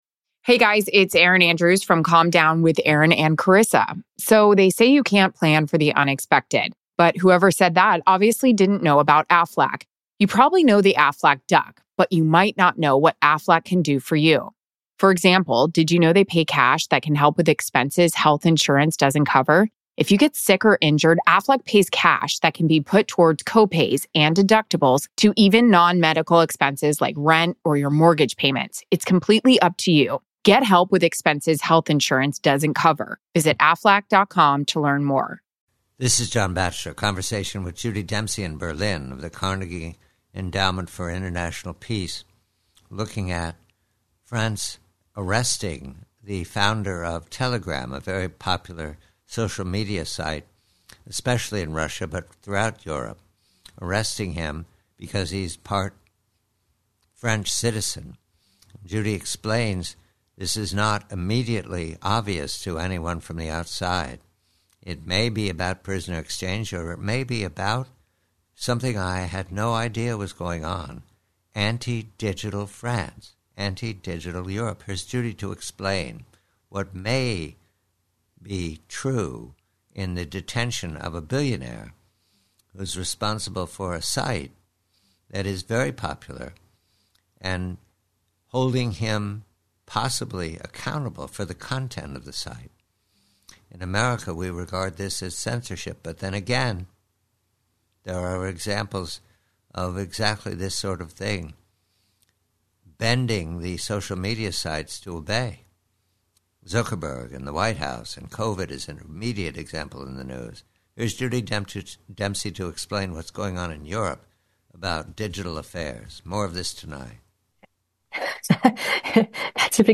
PREVIEW: FRANCE; MACROS: DIGITAL SERVICES. Conversation